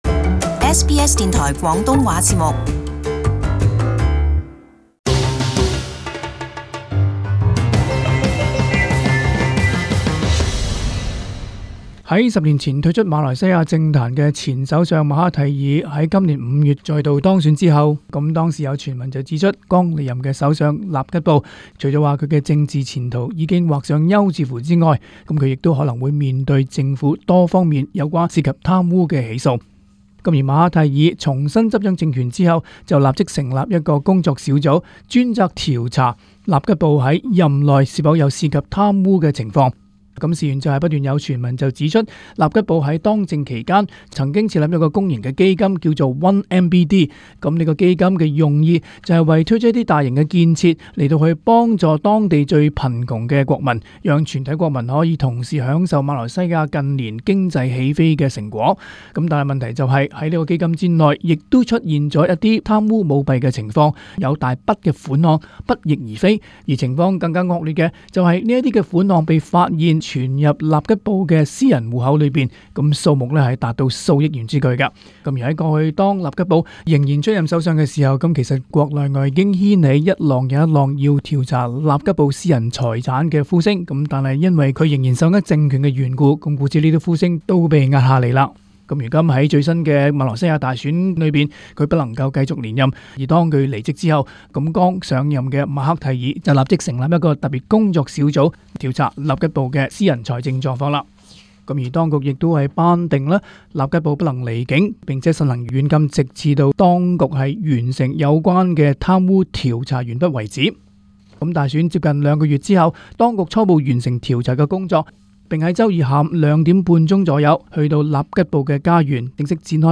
【時事報導】前馬來西亞首相納吉布被控貪污罪